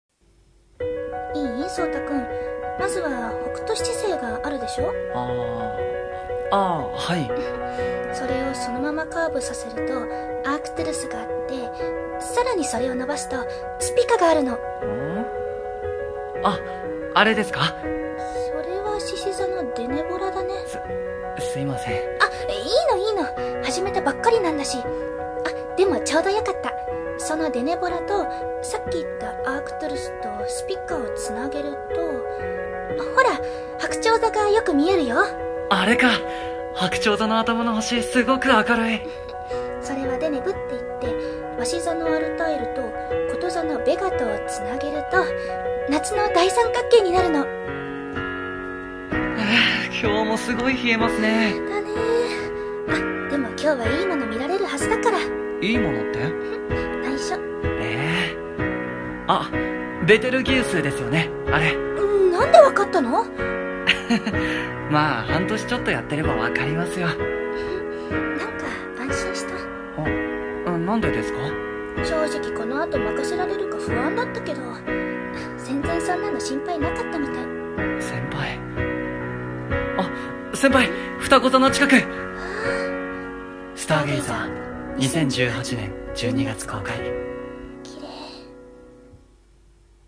【映画予告風声劇台本】Stargazer